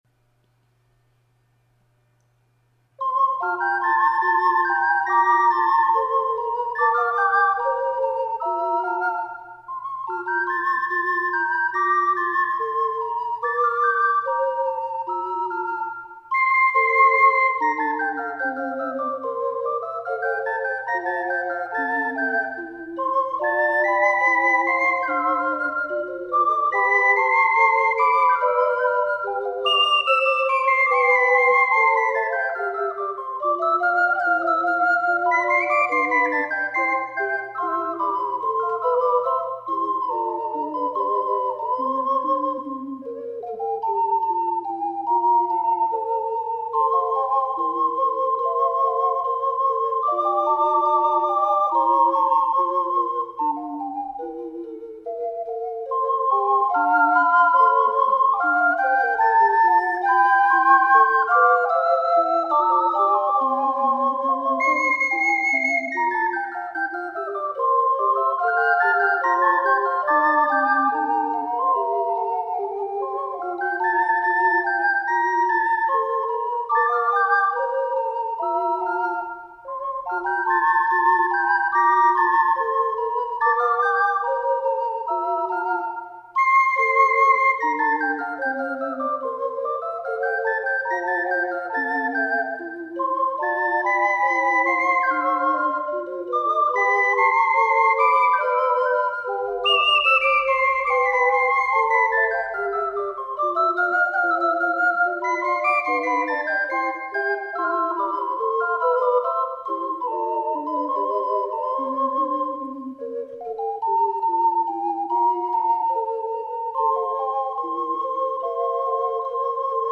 五重奏
試奏はBC管です。